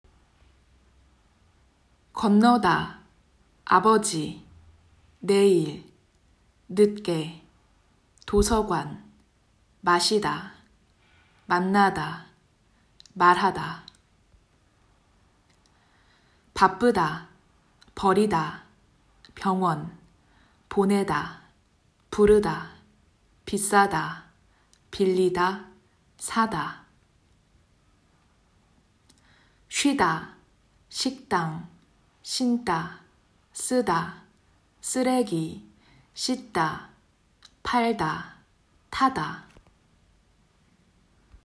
7_7__vocabulary.m4a